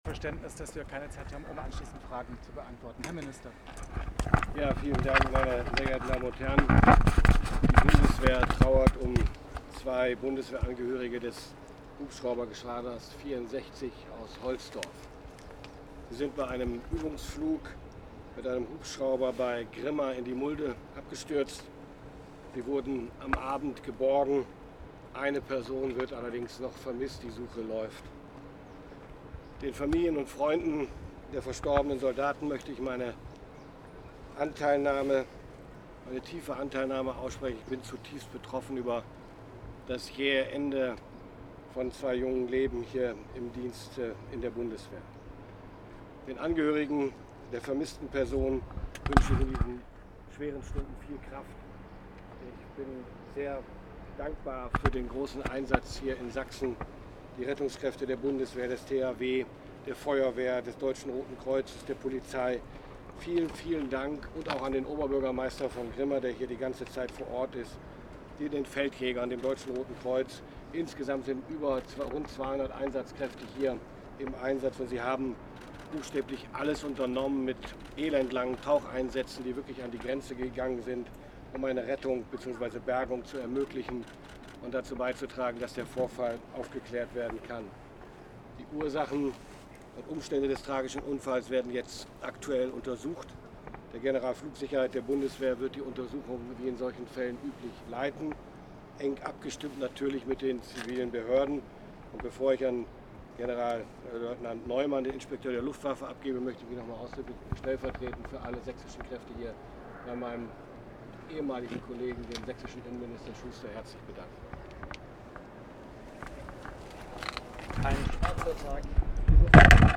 Statement des Verteidigungsministers und des Inspekteurs der Luftwaffe an der Absturzstelle des verunglückten Hubschraubers.